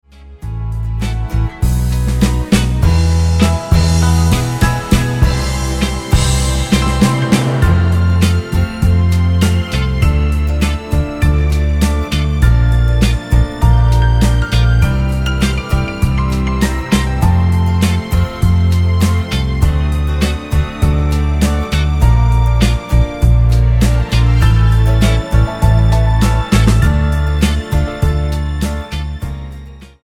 Tonart:E-F ohne Chor